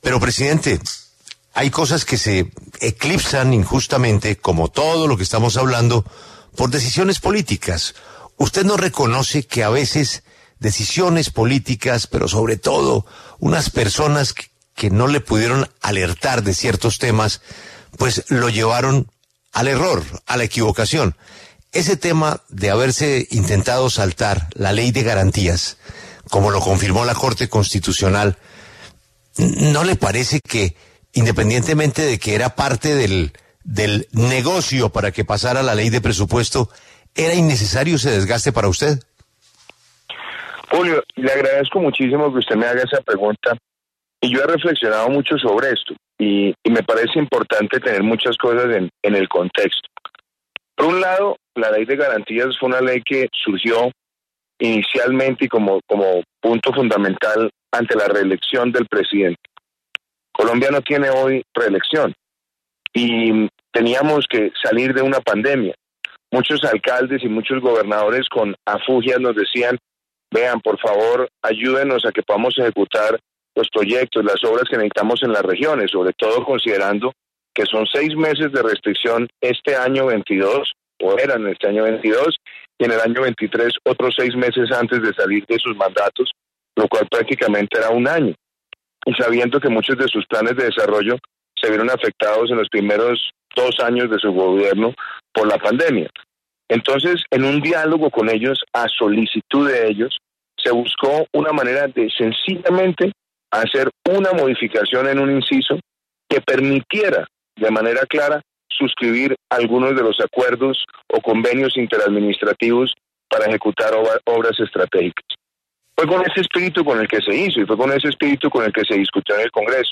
El presidente saliente aseguró en los micrófonos de La W que respeta la decisión de la Corte Constitucional de tumbar la modificación a la ley, y asume su responsabilidad política.
En entrevista con La W, el presidente saliente de la República, Iván Duque, se refirió a las críticas que recibió por la Ley de Garantías de la cual fue promotor durante su gobierno.